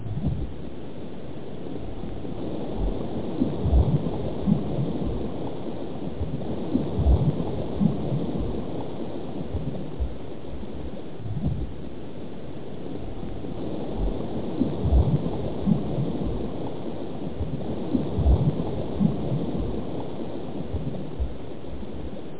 WaterLong.mp3